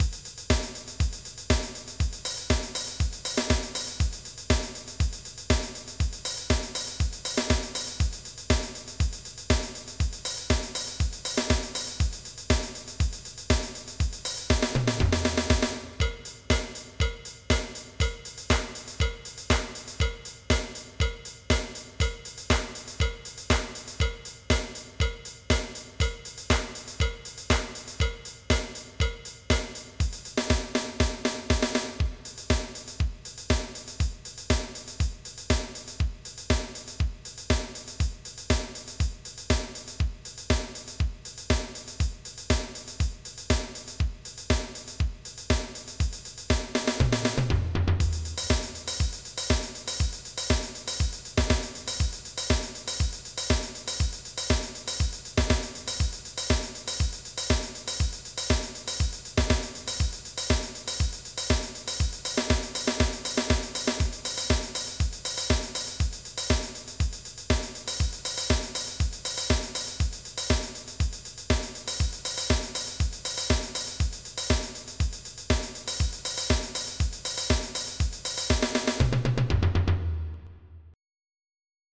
MIDI Music File
Type General MIDI
disco.mp3